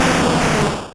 Cri de Kangourex dans Pokémon Rouge et Bleu.